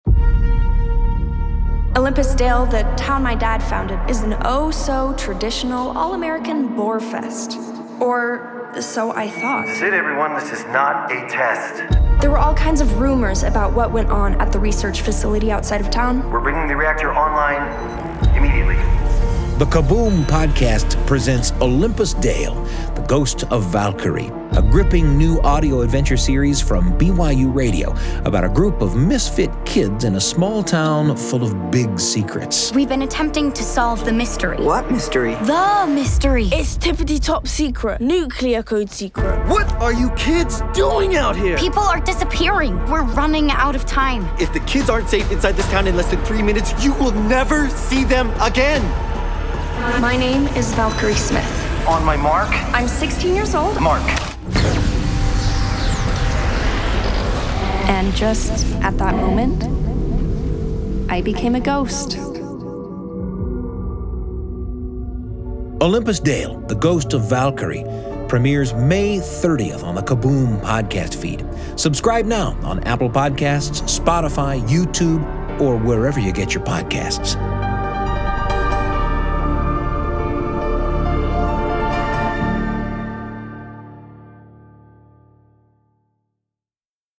Format: Audio Drama
Voices: Full cast
Narrator: First Person
Genres: Science fiction
Soundscape: Sound effects & music